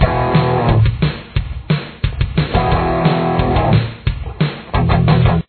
This riff is pretty standard and is in drop D tuning.
Guitar 2